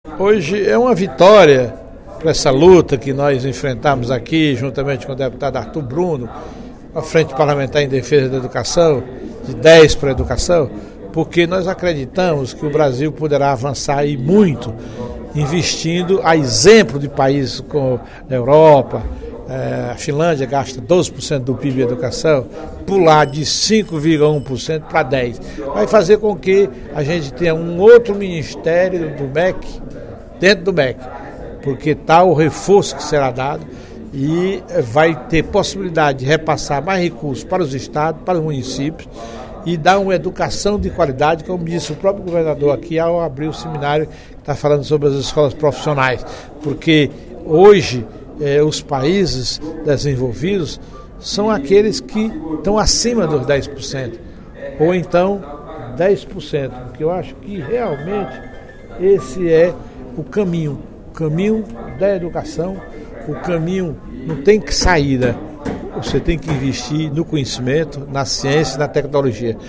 O deputado Professor Teodoro (PSD) afirmou, na sessão plenária da Assembleia Legislativa desta quarta-feira (27/06), que a aprovação do Plano Nacional de Educação (PNE) pela Câmara Federal representa um avanço para o sistema público de ensino brasileiro, pois destina 10% do Produto Interno Bruto (PIB) para o setor.